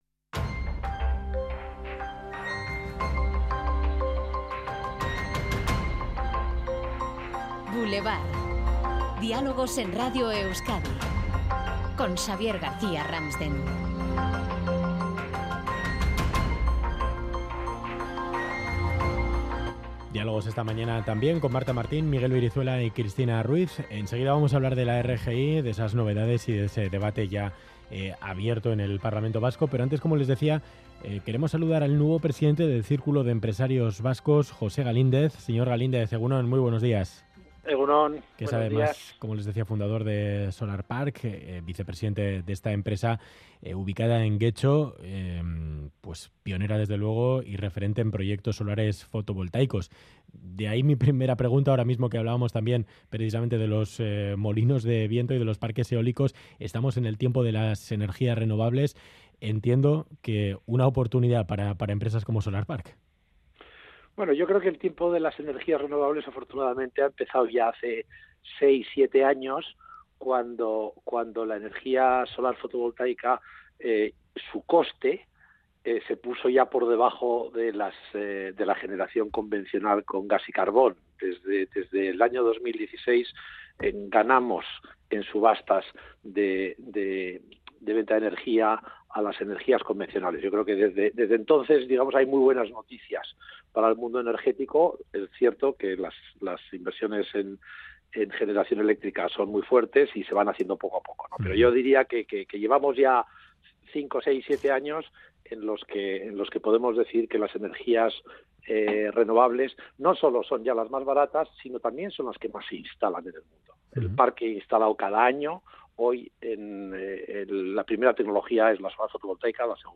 entrevista en Radio Euskadi.